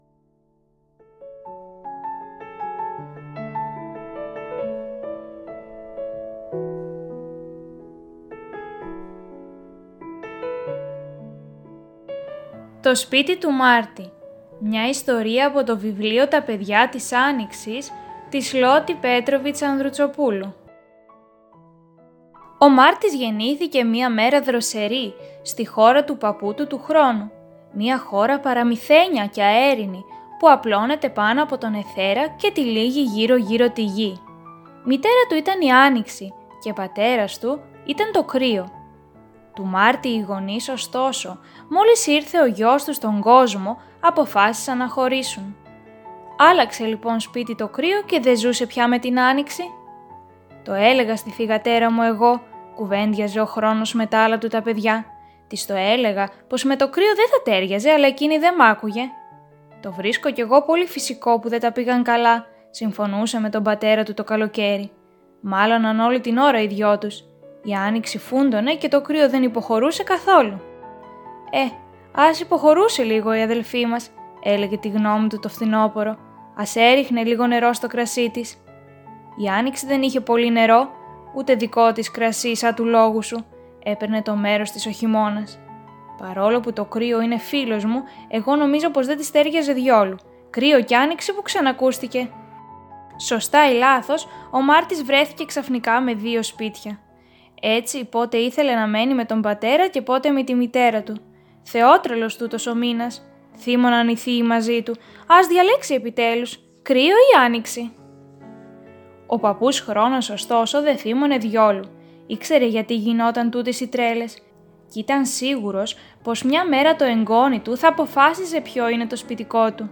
Βιβλιοθήκη Ψηφιακής Αφήγησης